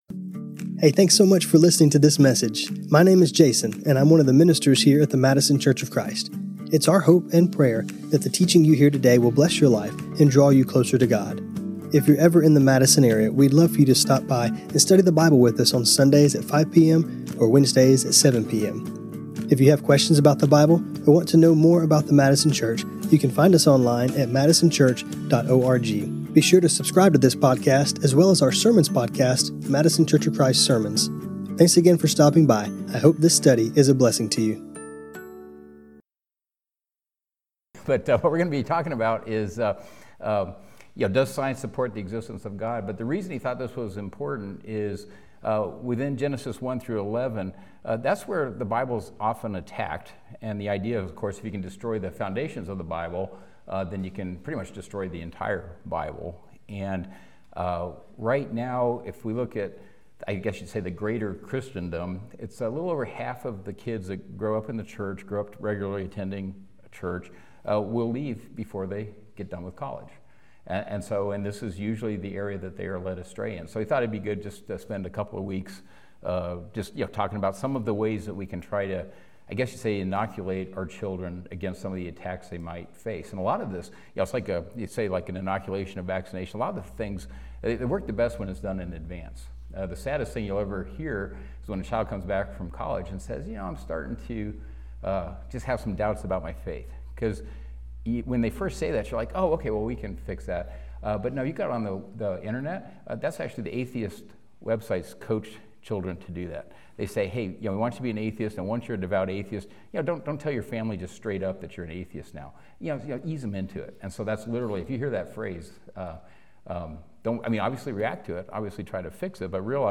Empty Nester Bible Study